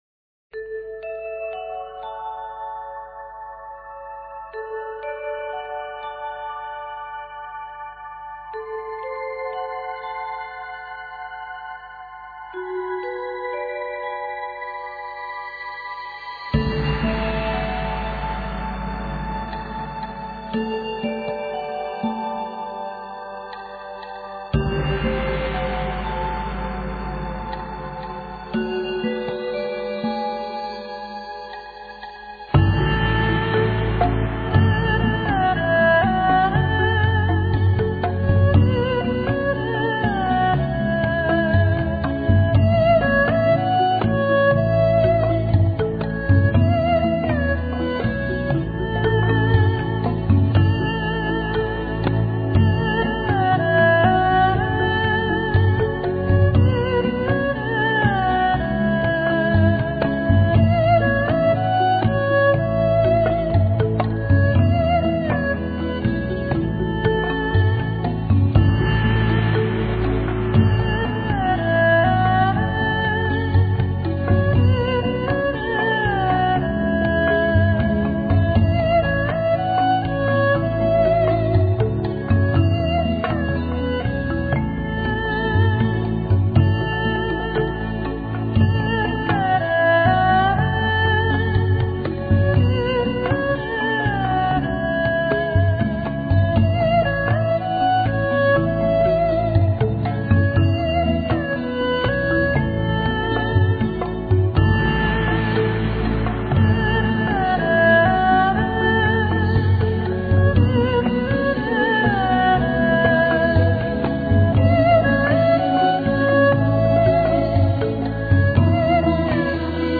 西塔琴
印度鼓
一張考證嚴謹的佛教咒語唱頌專輯
加上印度西塔琴、印度鼓、印度笛和中國二胡的詮釋
天籟般的純淨歌聲 搭配中國、印度傳統樂器